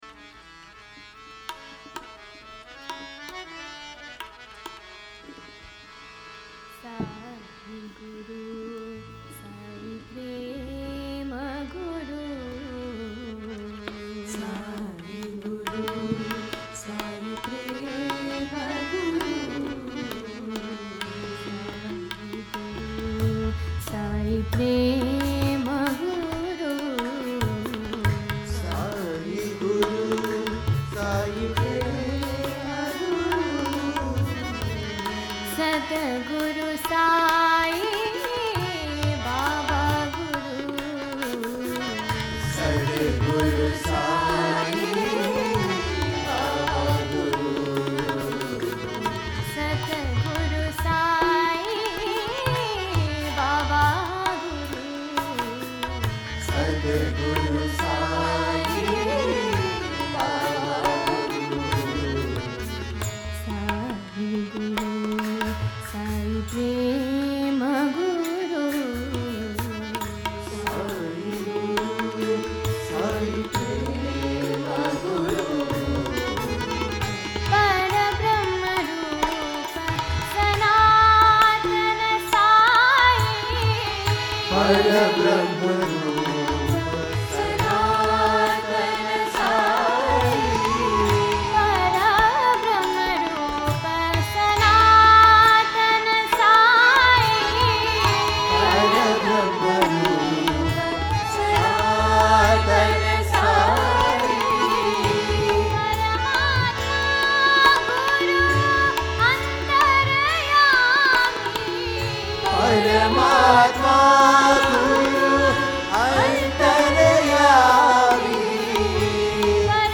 1. Devotional Songs
Yaman Kalyan 8 Beat  Men - 1 Pancham  Women - 5 Pancham
Yaman Kalyan
8 Beat / Keherwa / Adi
Medium Slow